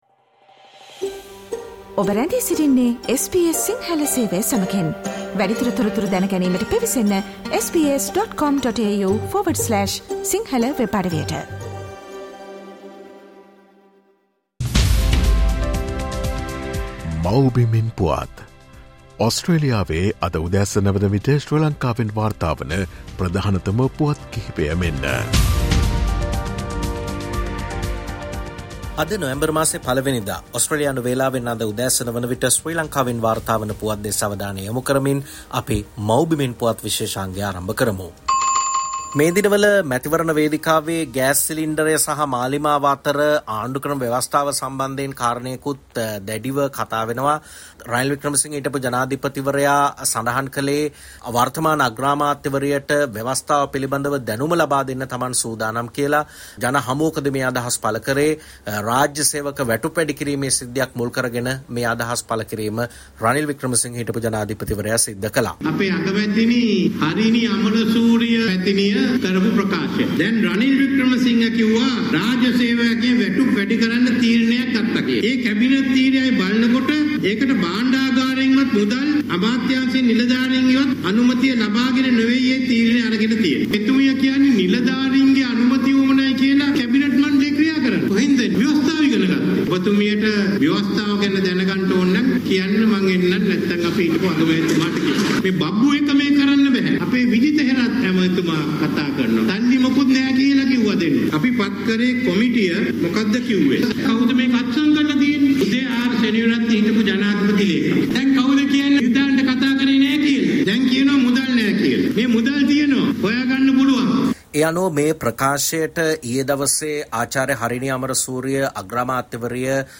Ranil and Harini clash over knowledge of constitution: Homeland news 01 November